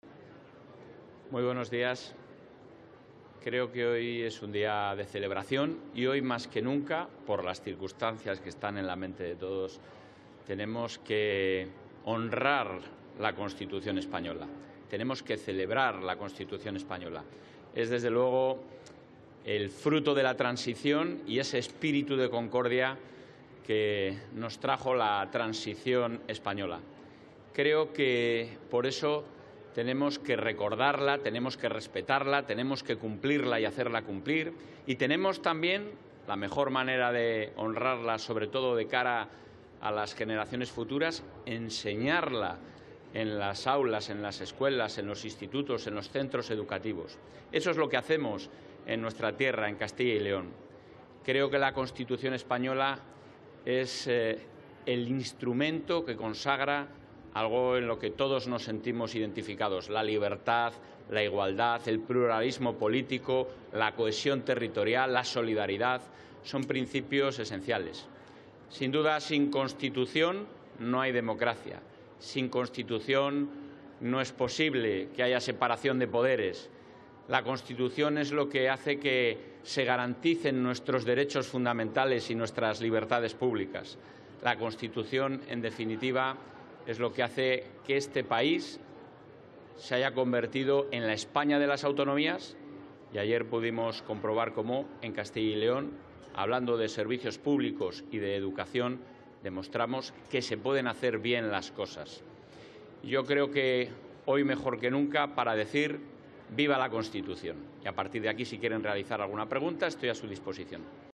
Declaraciones del presidente de la Junta.